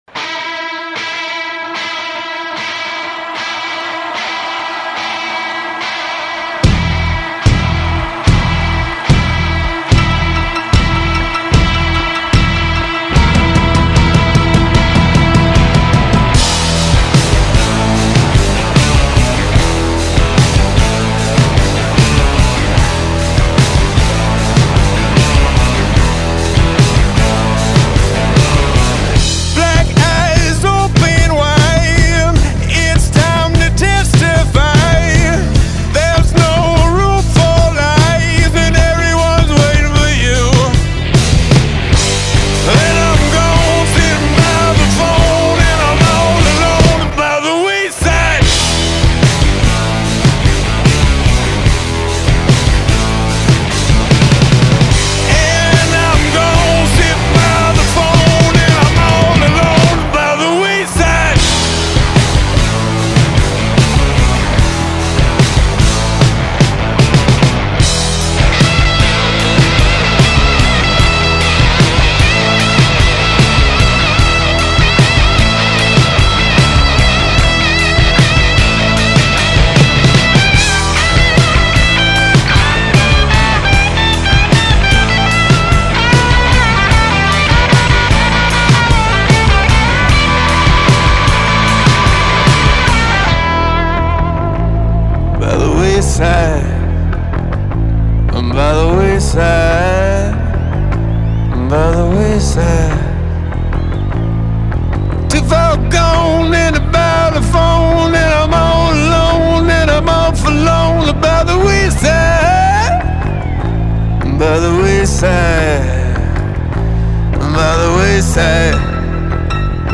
Soundtrack, Pop, Rock